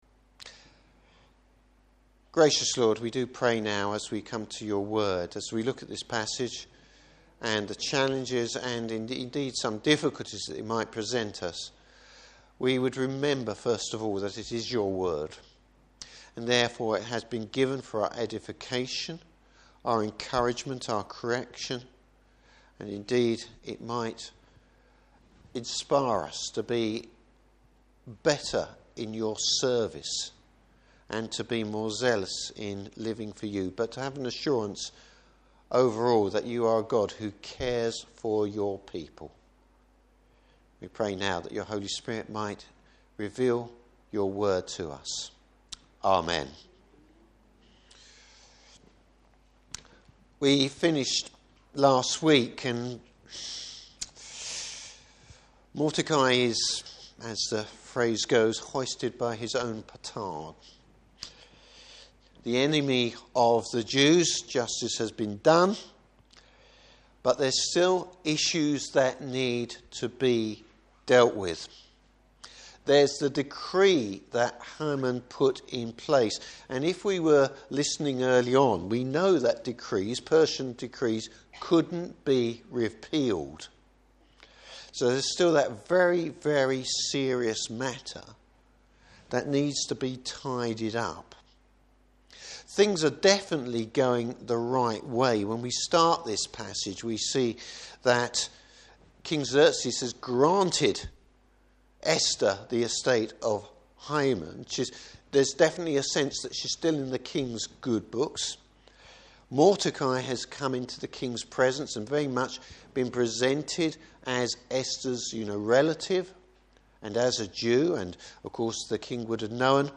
Service Type: Evening Service Judgement through unconventional means!